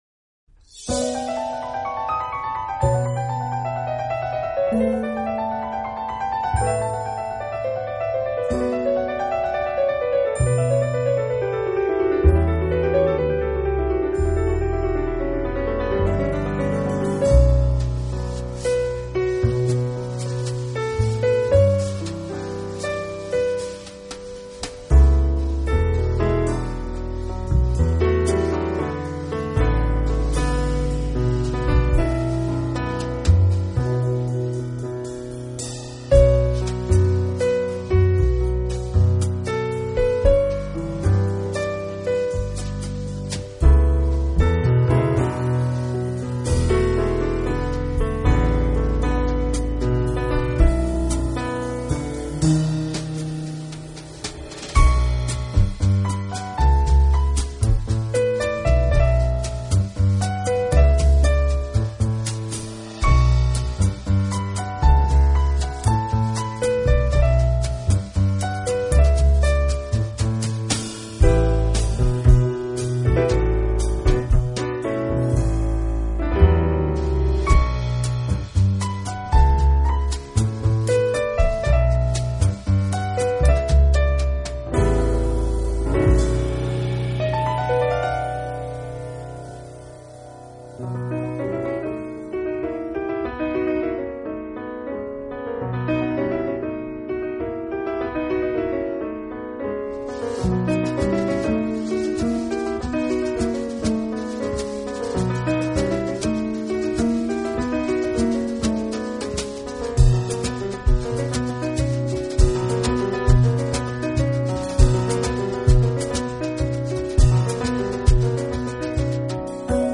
这是一张最令人放松的圣诞音乐专辑。
专辑中新增加的凯尔特风格，这是非常可爱的转变，你可以从整张专辑上感受到
听这张专辑让你感到很悠闲，根本不会让你感到匆忙，找个舒服的椅子坐